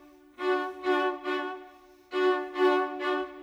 Modern 26 Viola 02.wav